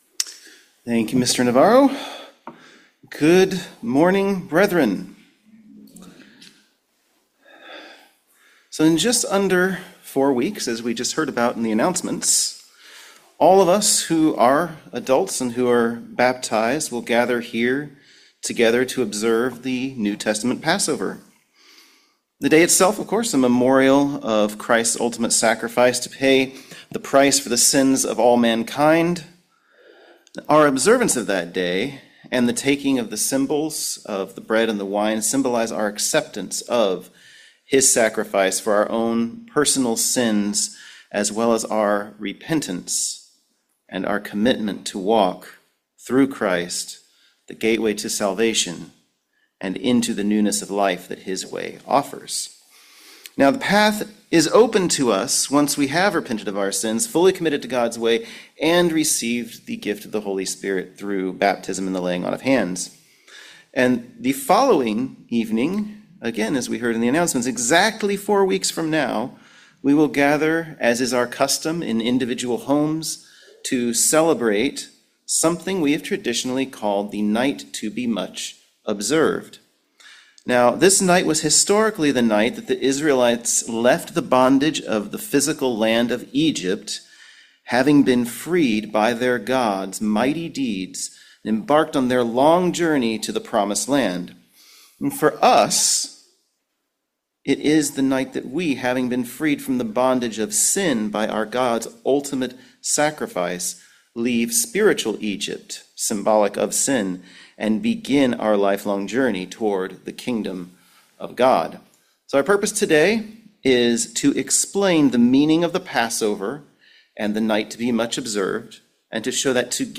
Sermons
Given in Raleigh, NC